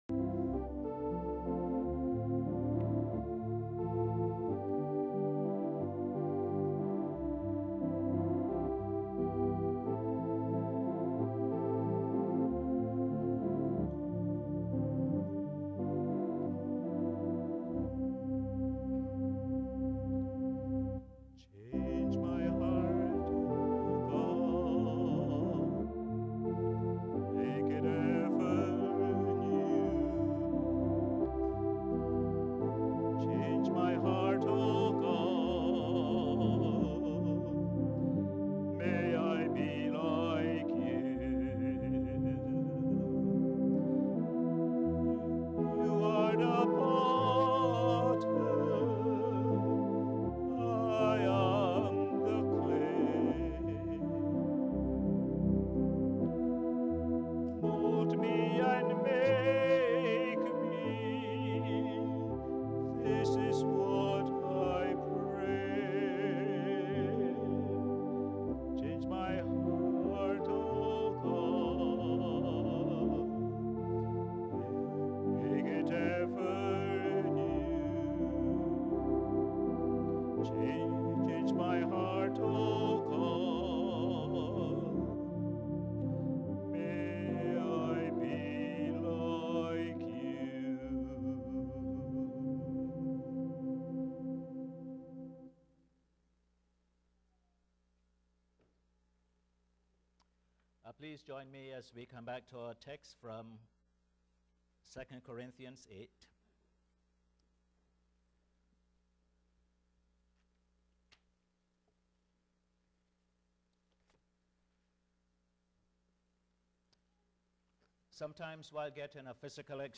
Posted in Sermons on 27. Sep, 2011